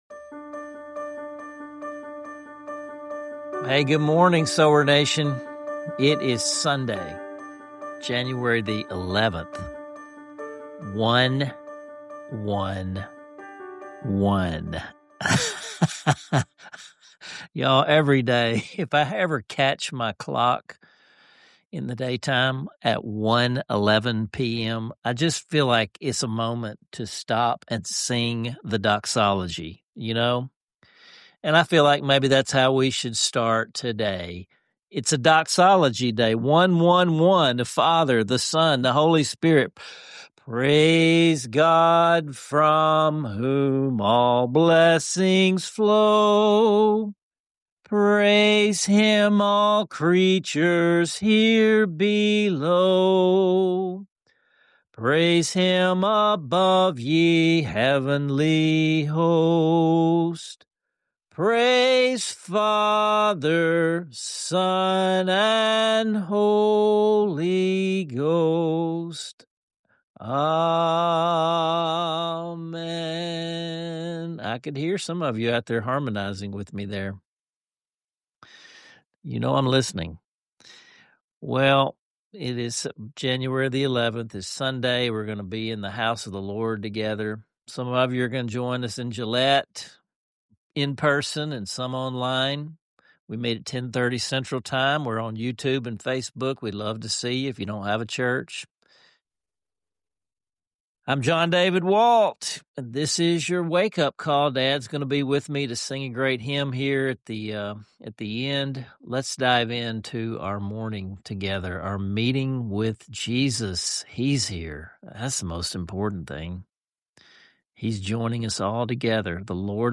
Plus — you’ll experience the beauty of hymns and the joy of worship as part of the Seedbed tradition.